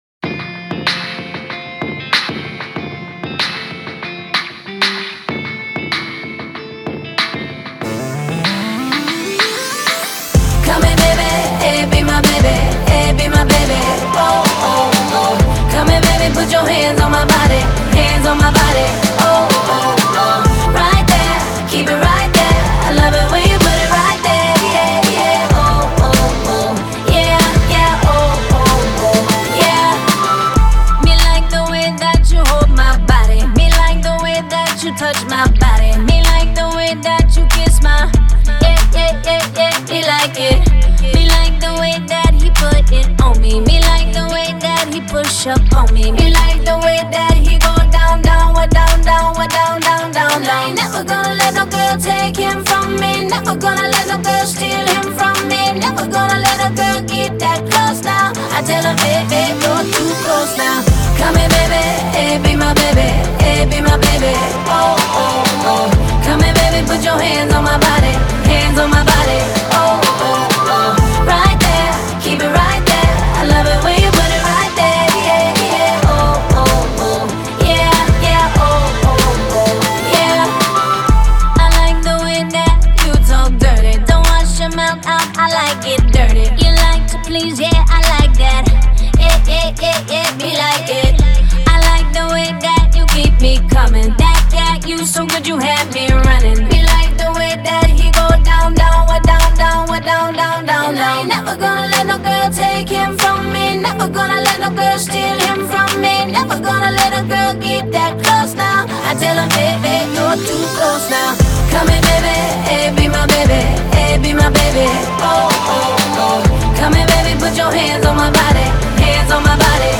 Жанр: Только качественная POP музыка